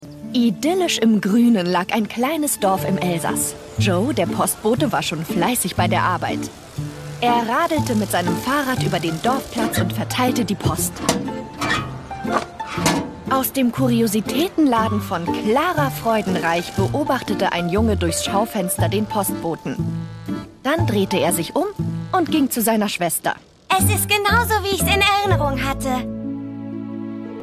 Hörspiel
Dino-Mates-Hoerprobe.mp3